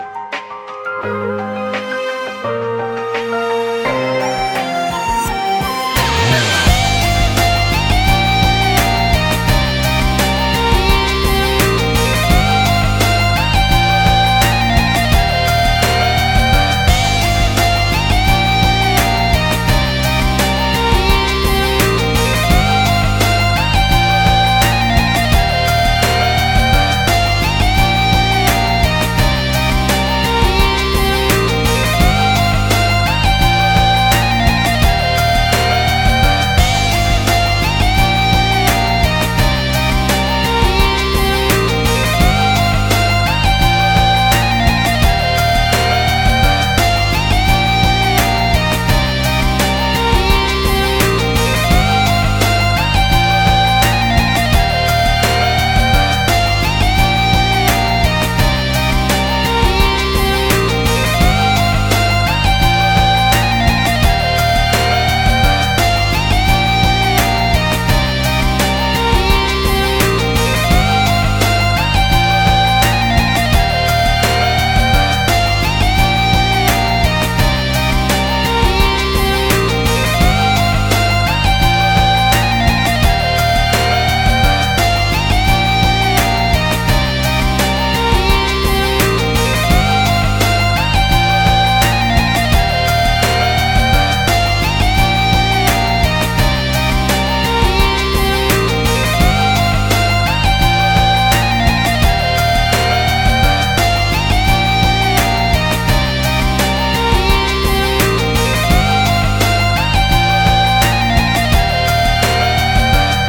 Ps：在线试听为压缩音质节选，体验无损音质请下载完整版 无歌词